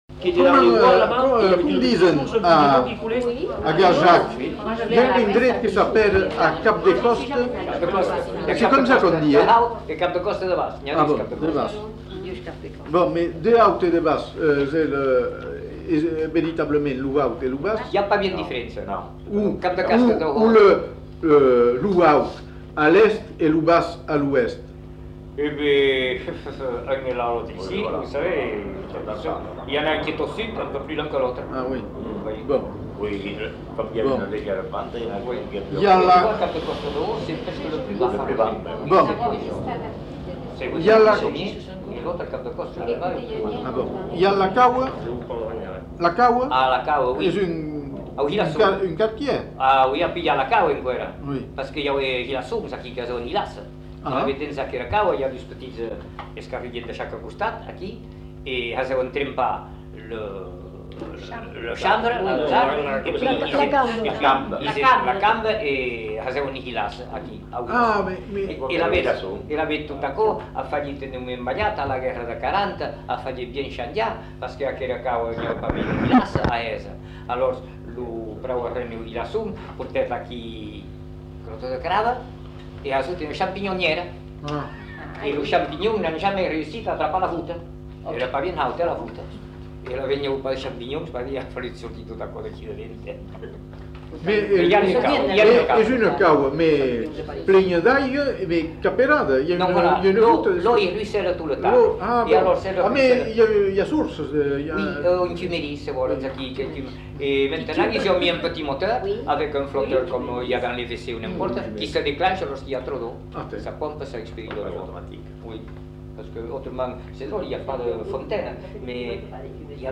Genre : témoignage thématique
[enquêtes sonores]